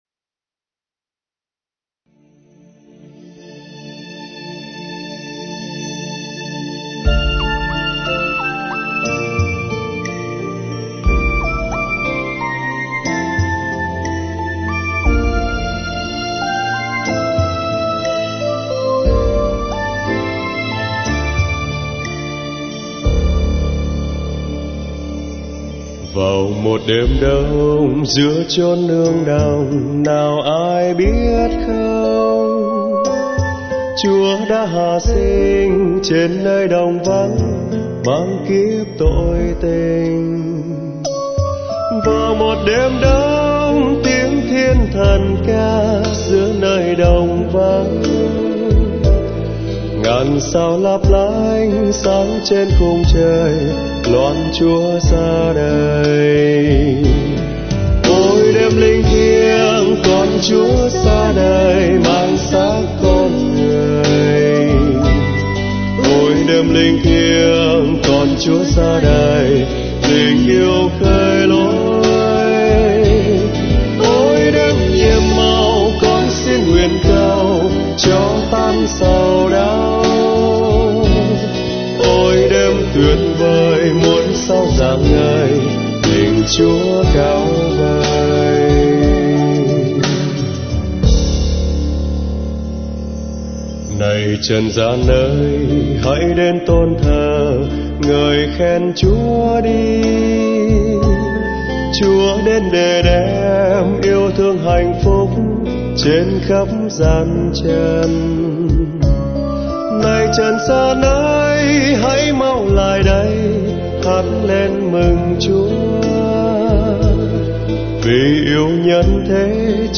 Dòng nhạc : Giáng Sinh